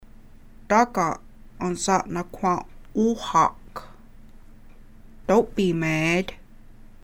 Click below each question, command or answer to hear the Oneida and English pronunciations.